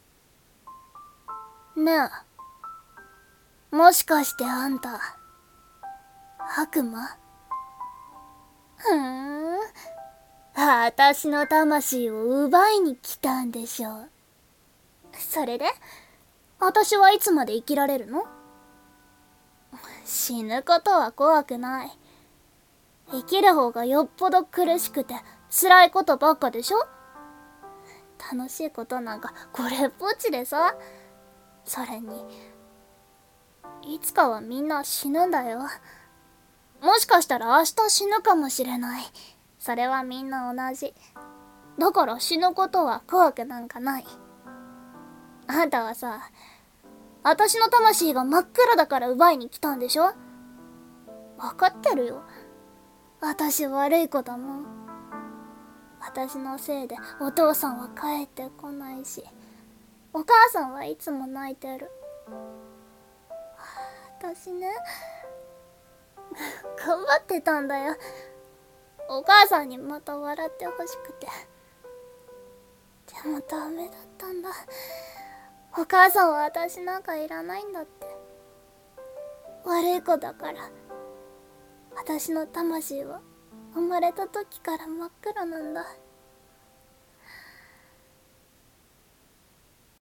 声劇「悪魔と少女」